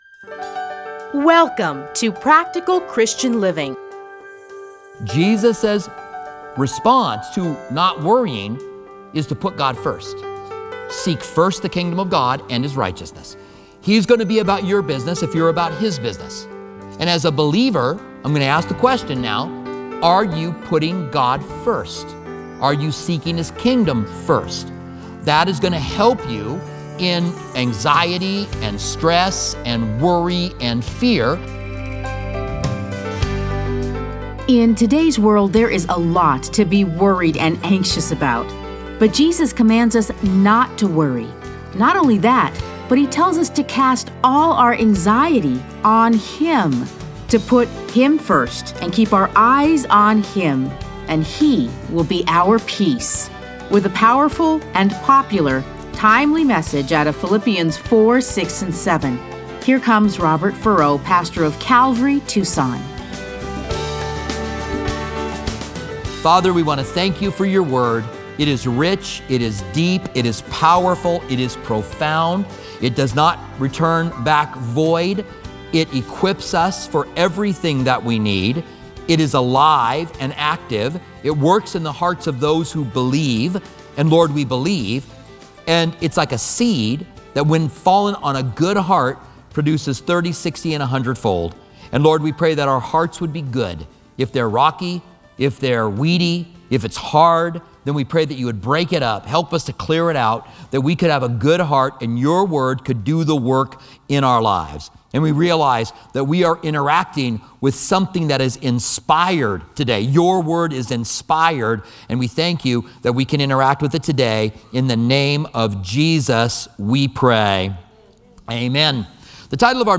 Listen to a teaching from A Study in Philippians 4:6-7.